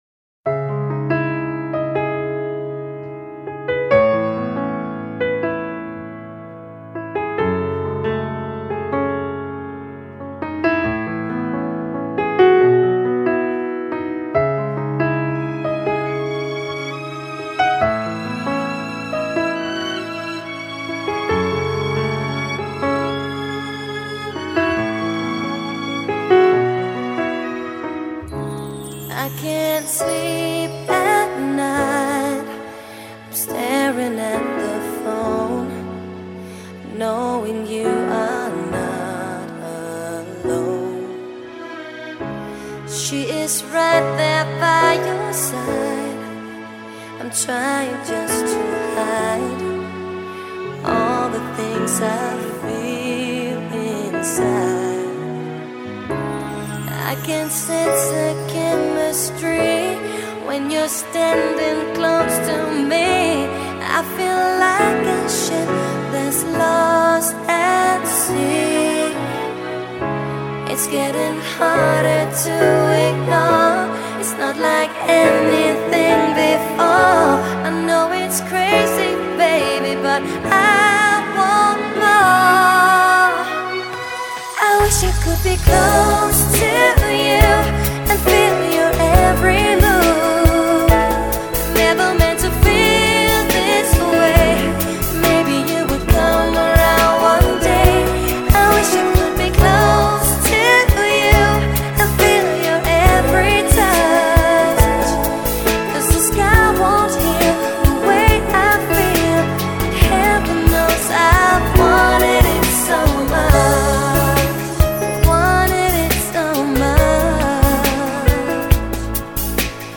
медленные песни
медленная музыка , Медляки